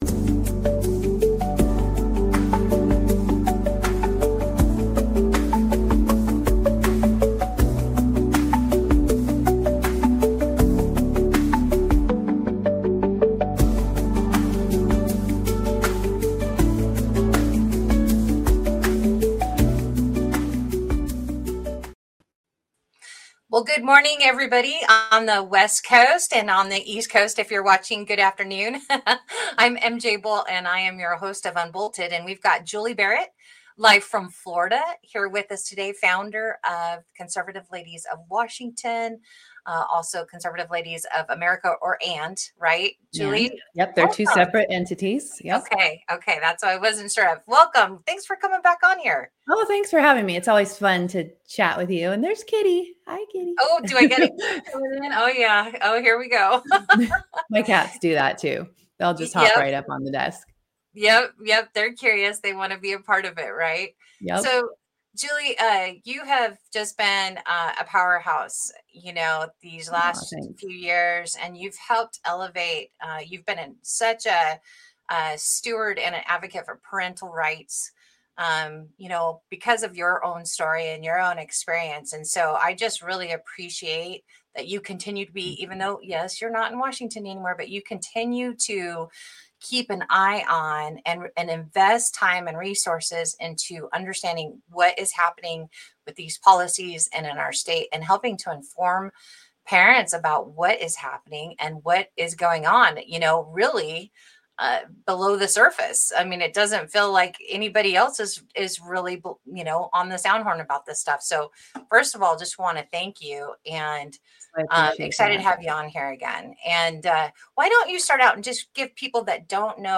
UnBolted LIVE